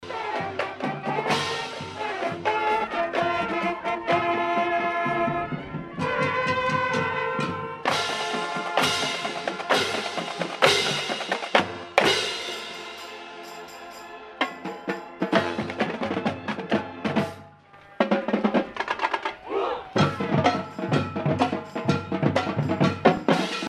Downtown Manhattan lights up for annual Spirit of the Holidays parade
Manhattan High School’s Marching Band played many holiday songs while glide-stepping in formation.